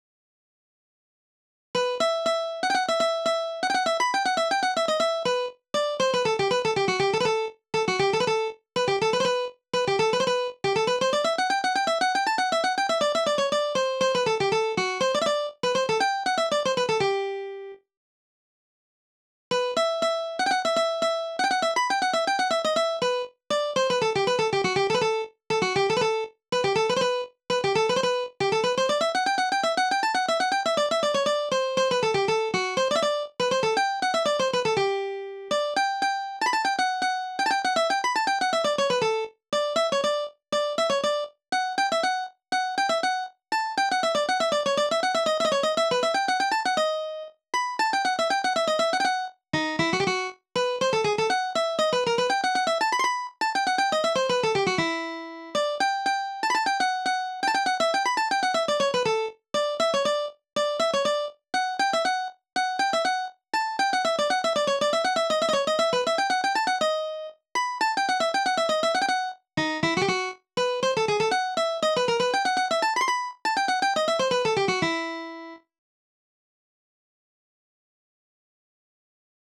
DIGITAL SHEET MUSIC - MANDOLIN SOLO